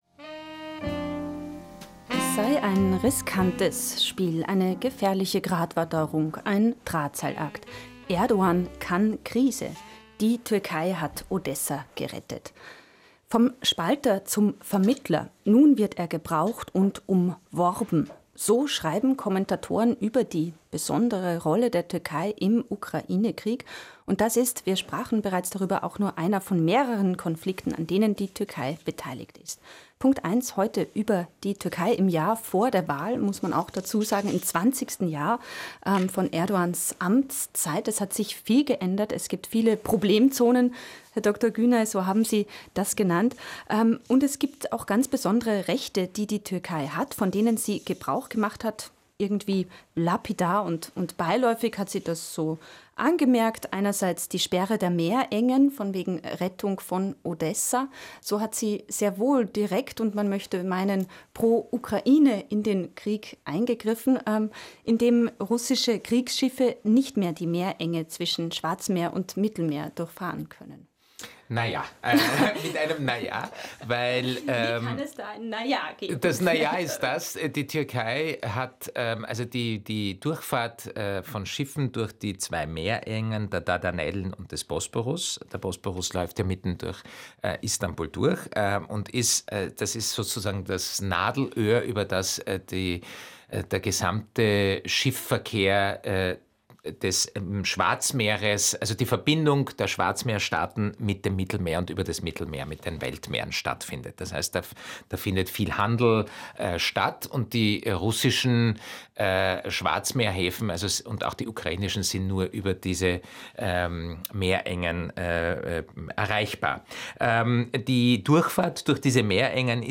Mitschnitt der Sendung: Der türkische Drahtseilakt im Ukraine-Krieg